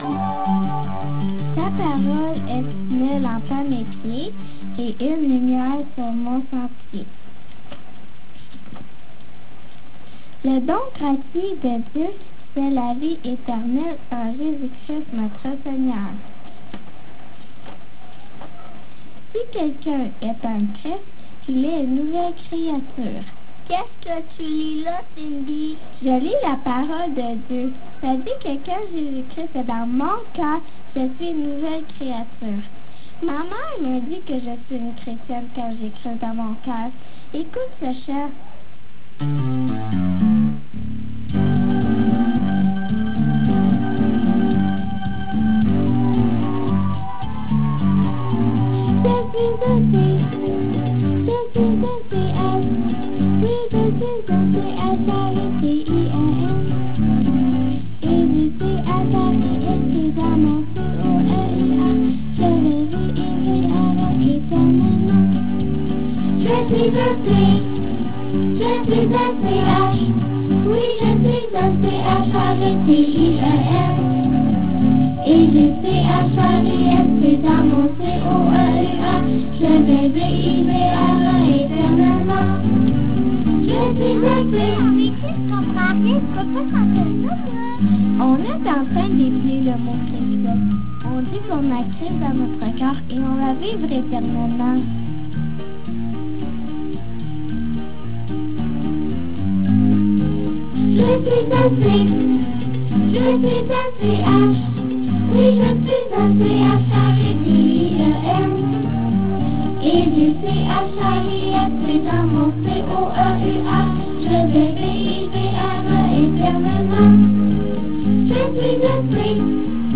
Chantés par des enfants